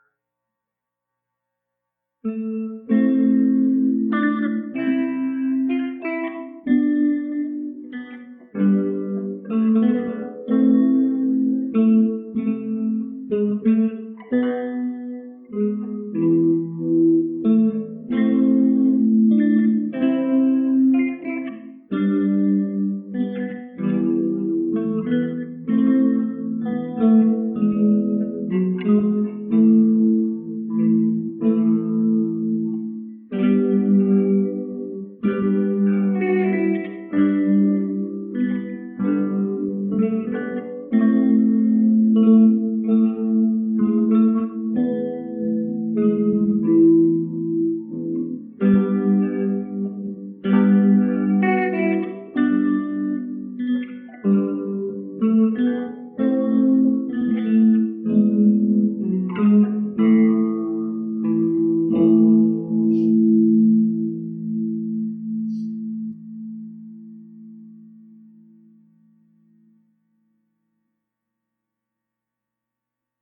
Okay, I set myself a task of learning a fingerpick version of “Greensleeves” on the guitar in time to release for Christmas.
So, to try to cover the sound of various imperfect places, I recorded myself playing it five times and turned it into an “ensemble” recording as if of five guitars playing Greensleeves rather inexpertly.